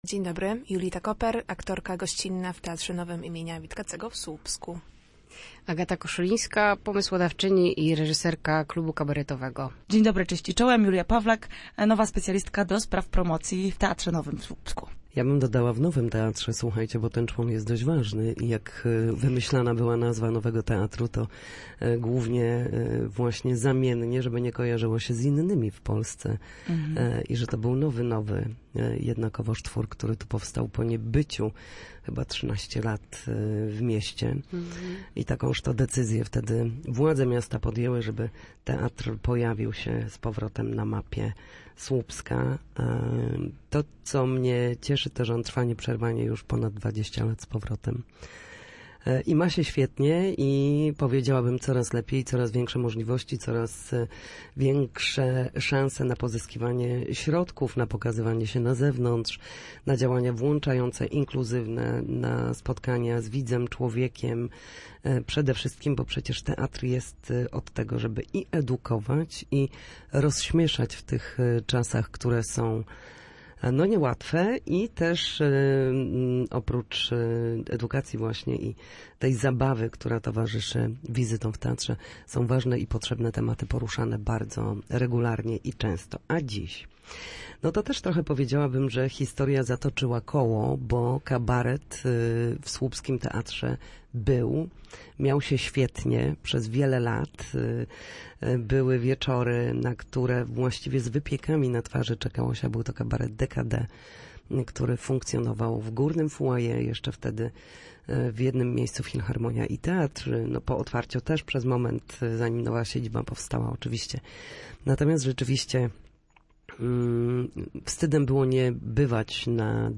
W Studiu Słupsk opowiedziały o niej